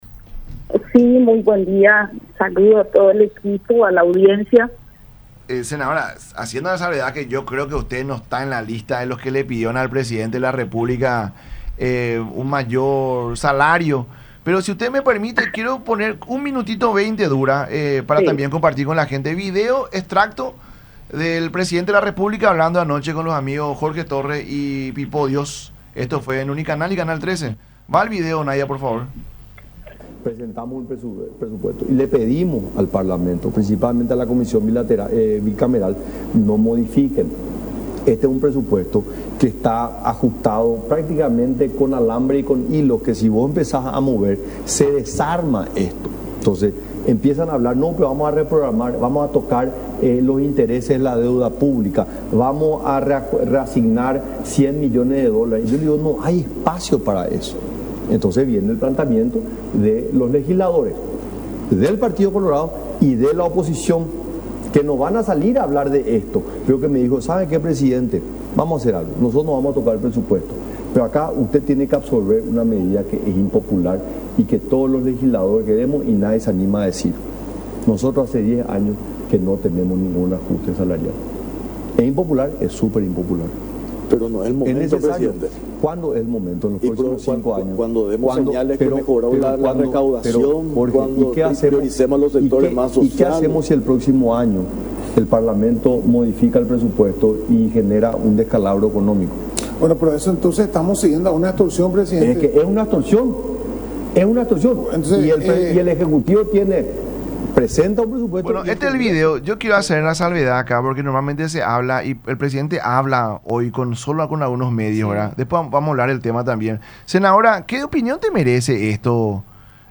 “Vamos a oponernos, hay opositores no opositores”, sentenció en el programa “La Gran Mañana De Unión” por radio La Unión Y Unión Tv.